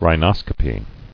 [rhi·nos·co·py]